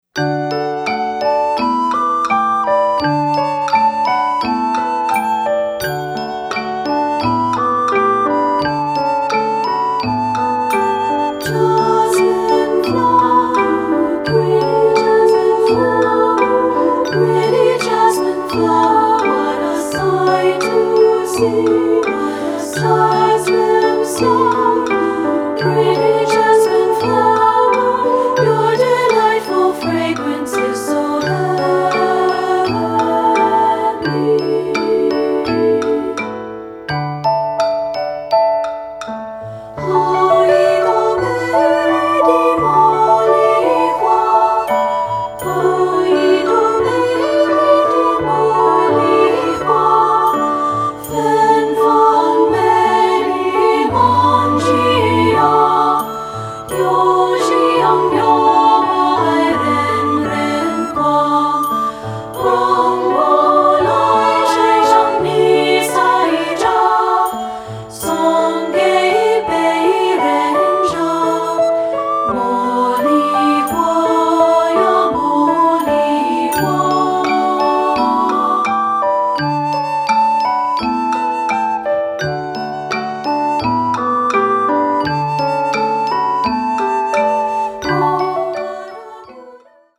Choral Multicultural Women's Chorus
Chinese Folk Song
SSA